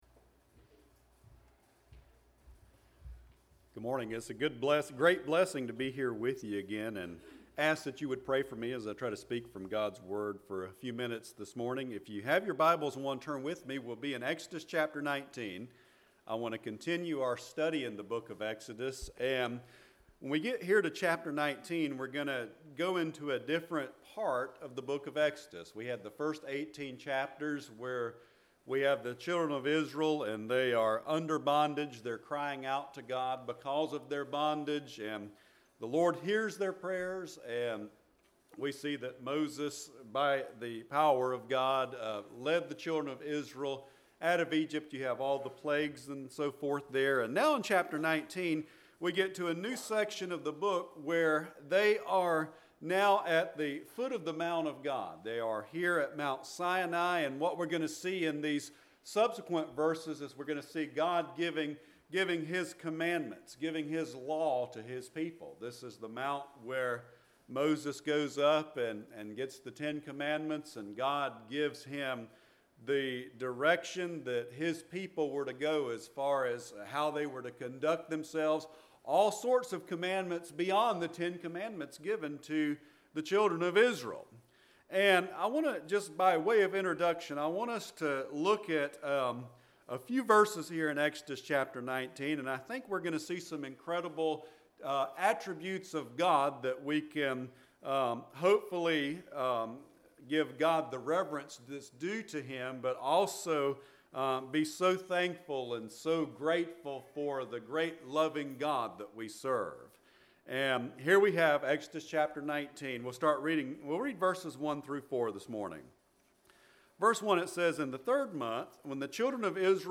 10/16/2022 Sunday Morning
Service Type: Sunday Morning